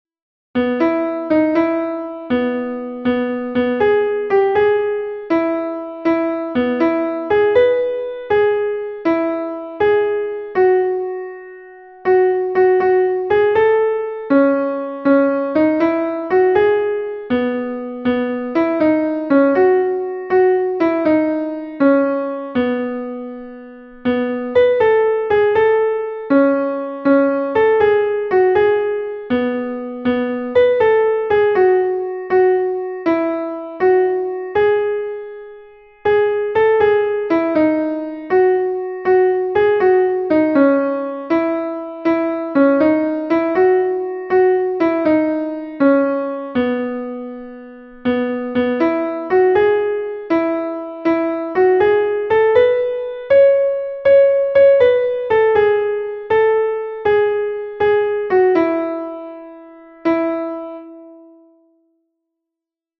Sentimenduzkoa
Hamarreko handia (hg) / Bost puntuko handia (ip)